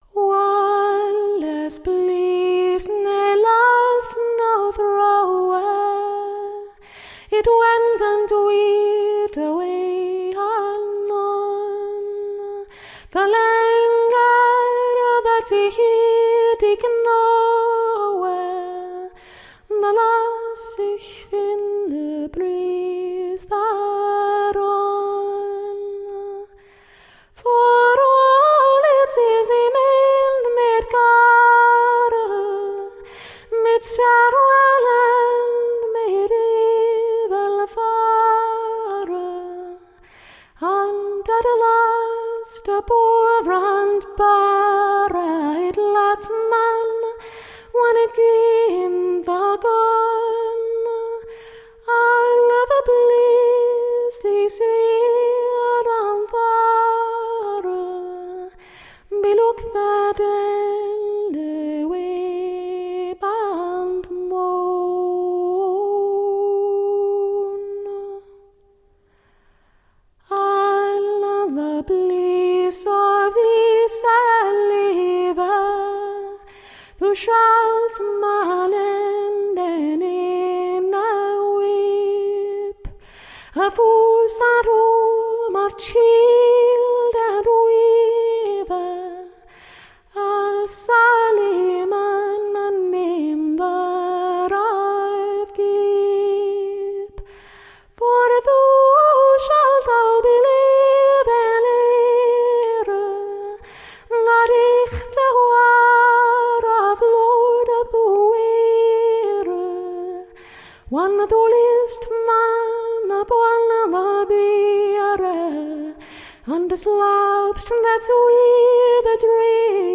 13th century medieval english songs.
Classical, Medieval, Classical Singing, Flute, Harp